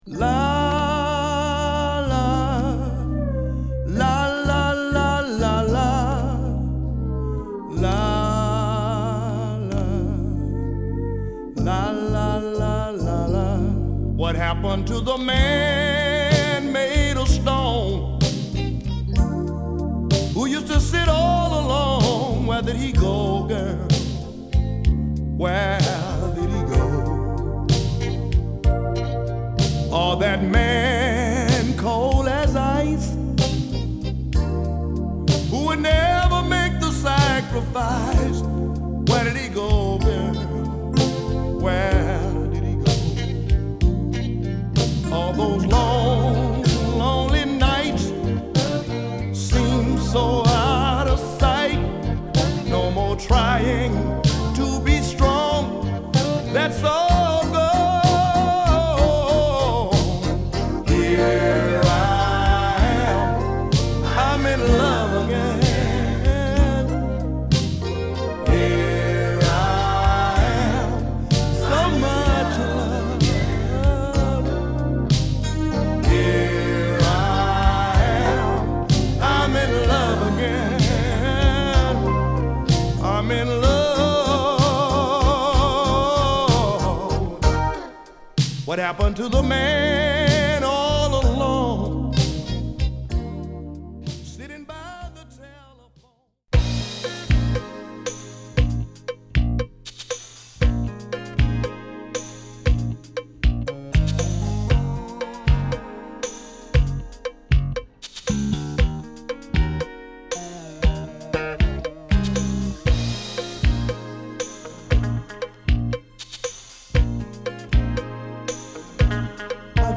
シカゴソウルに欠かせないシンガーの一人として活躍